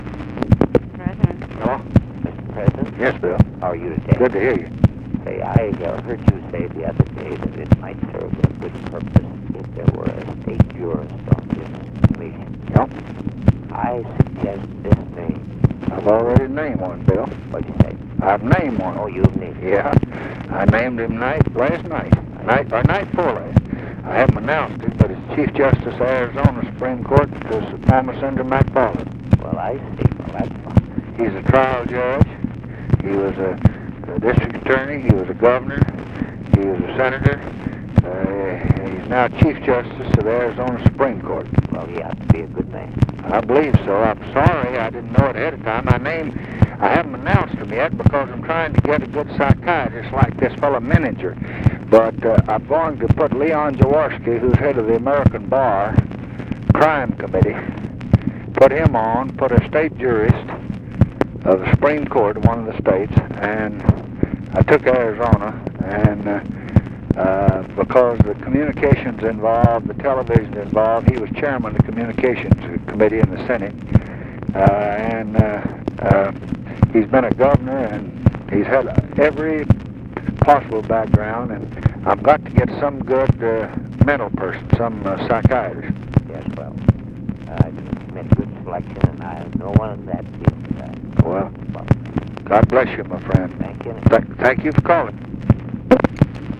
Conversation with WILLIAM MCCULLOCH, June 21, 1968
Secret White House Tapes